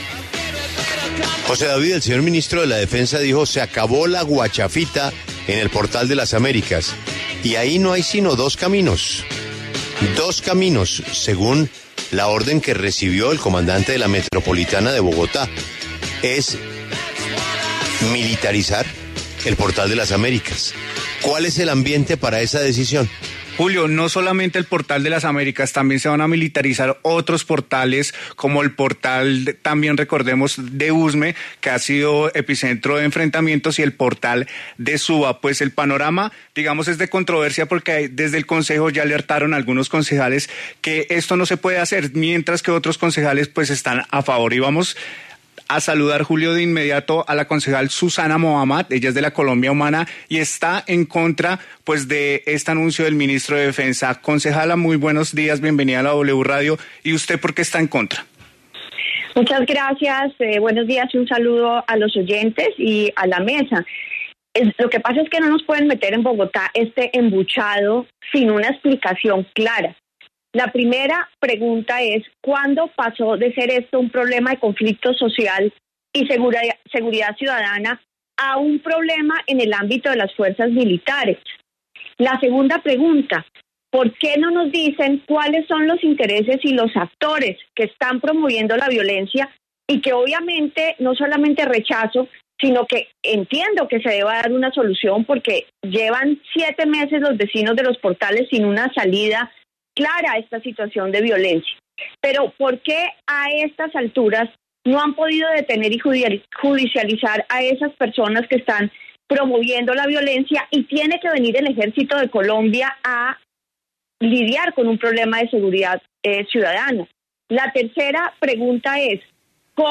La concejal Susana Muhammad y Humberto ‘Papo’ Amín debaten en La W sus razones para apoyar o no el anuncio del Ministerio de Defensa.